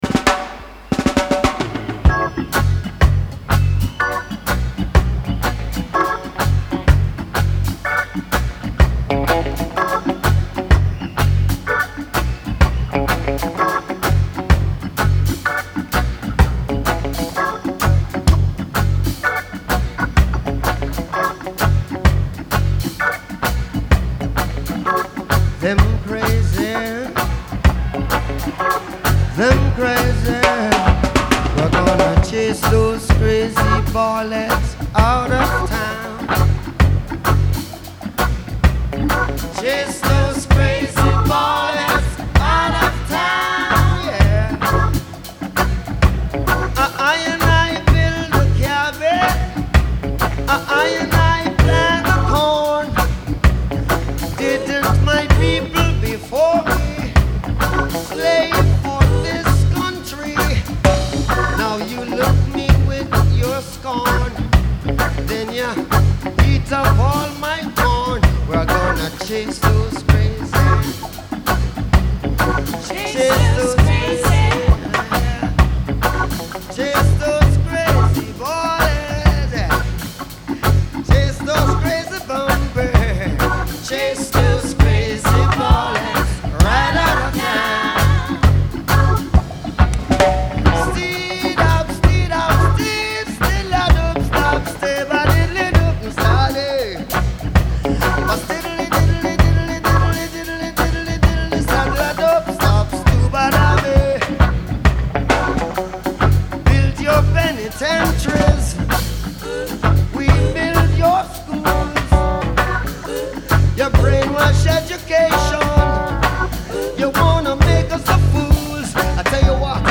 Genre : Reggae, Musiques du monde
Live At The Rainbow Theatre, London